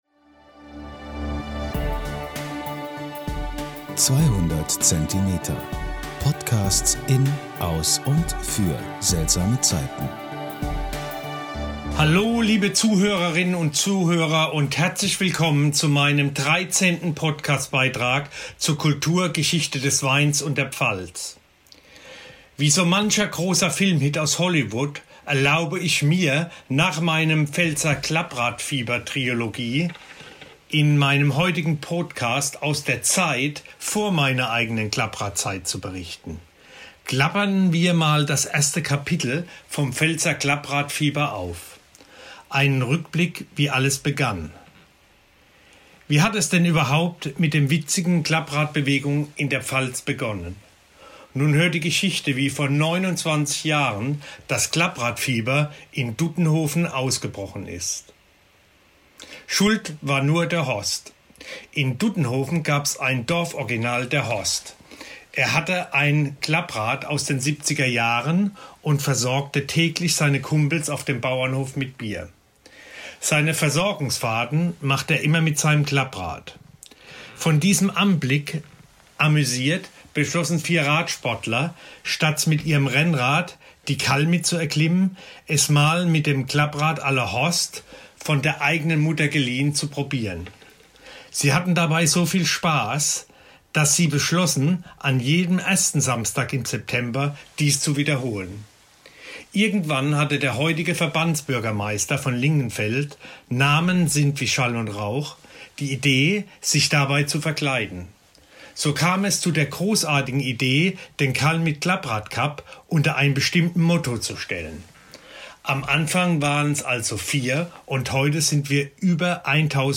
Hier die komplette „Saga“ auf einer Seite zum Download – als Hörbuch, oder in einzelnen Abschnitten.